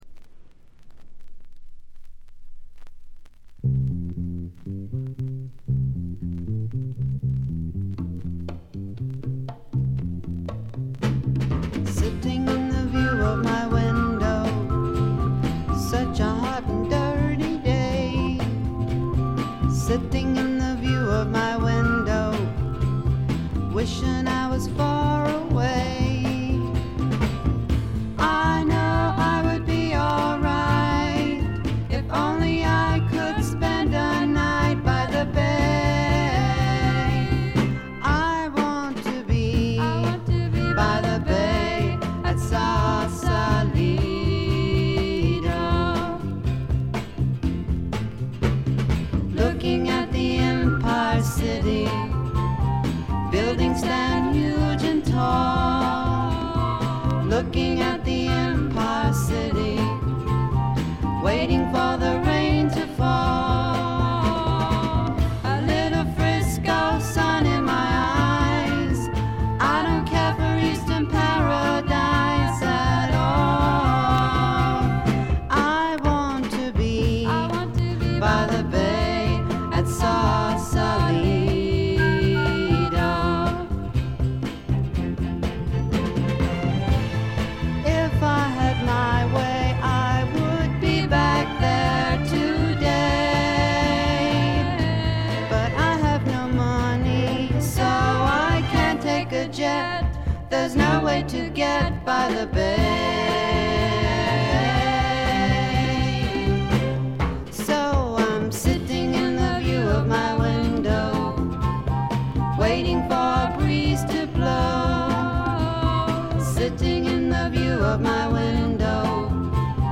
軽微なバックグラウンドノイズ、チリプチ程度。
ソフト・ロック、ソフト・サイケ、ドリーミ・サイケといったあたりの言わずと知れた名盤です。
試聴曲は現品からの取り込み音源です。